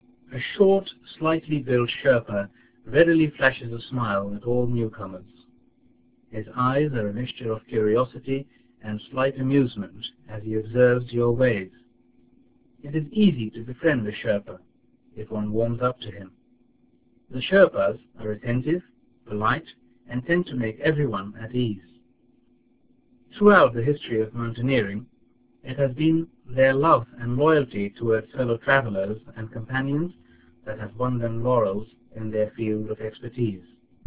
A traveller befriends a Sherpa.